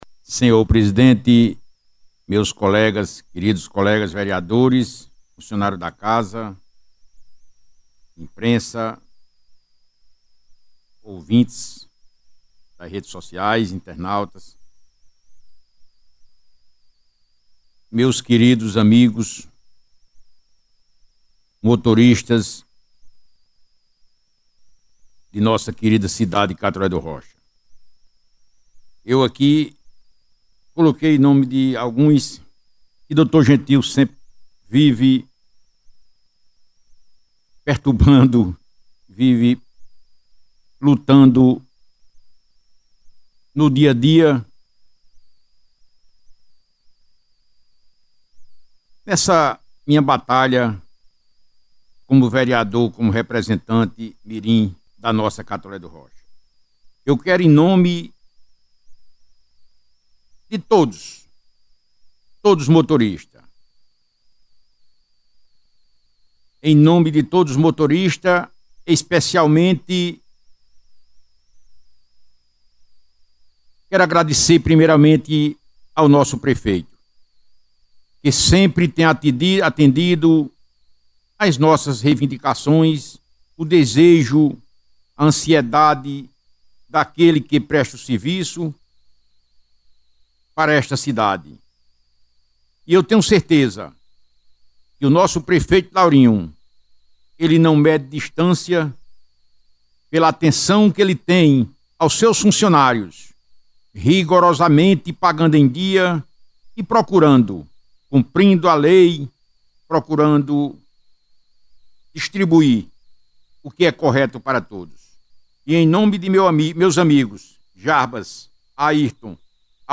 Durante a Sessão Extraordinária da Câmara Municipal de Catolé do Rocha, o vereador Dr. Gentil Barreto fez um pronunciamento firme, emocionado e carregado de reconhecimento ao trabalho dos motoristas do município, além de destacar a parceria constante com o prefeito Laurinho Maia em prol do servidor público e da população.